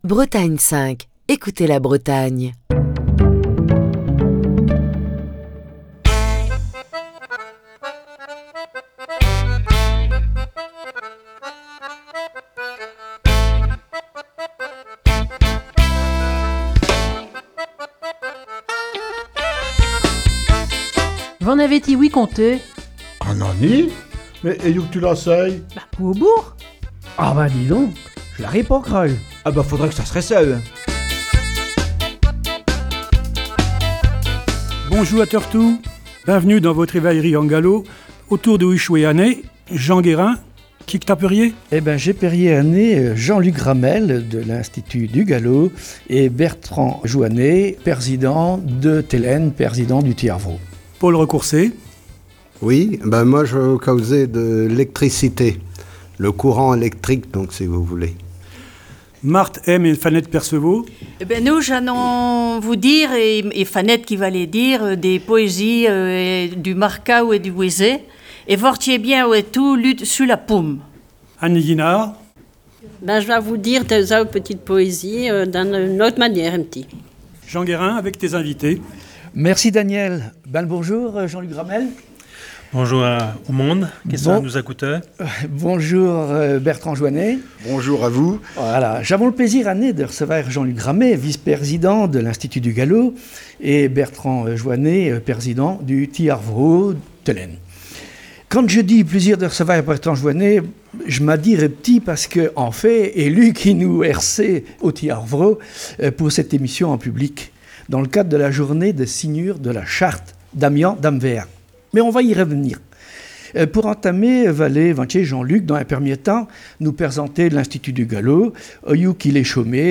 V'en avez ti-ouï conté, en direct depuis Ti Ar Vro Saint-Brieuc. Aujourd'hui, l'Institut du Galo et Telenn | Bretagne5